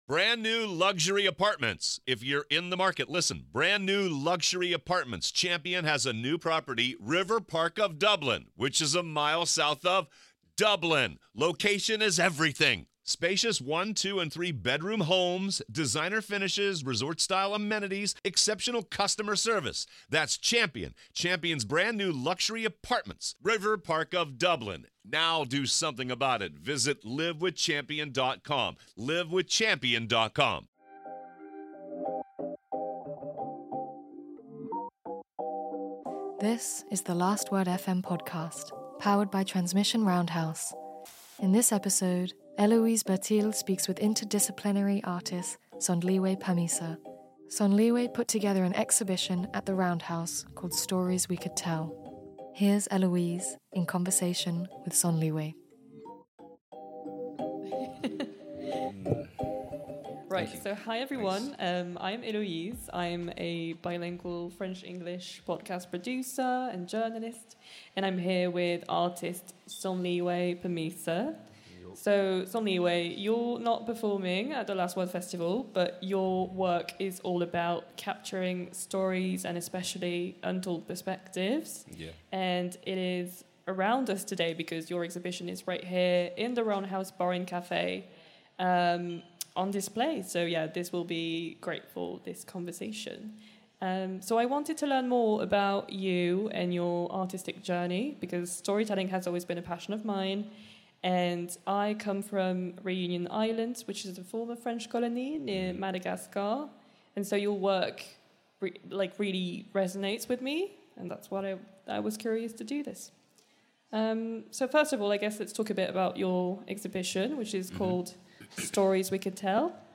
The Last Word FM is an annual live broadcast powered by Transmission Roundhouse with talks, interviews and panels with a number of incredible artists whose work was featured as part of The Last Word Festival.